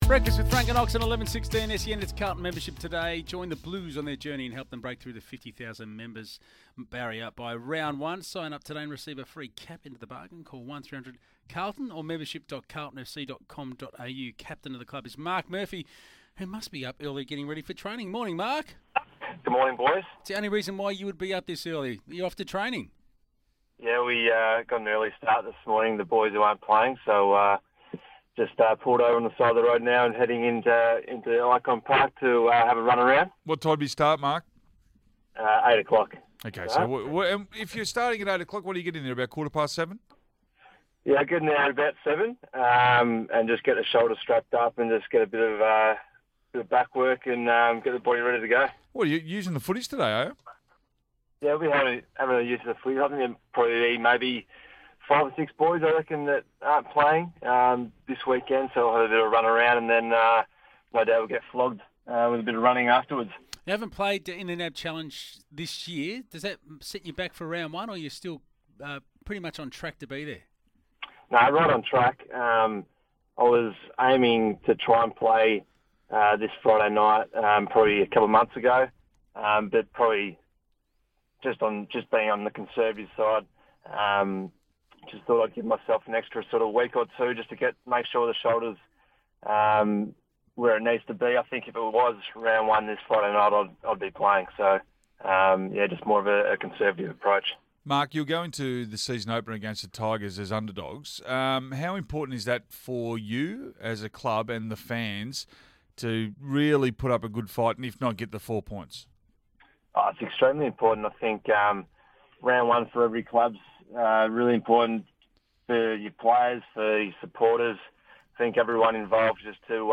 Captain Marc Murphy chats to Francis Leach and David Schwarz as part of SEN's Carlton Membership Day.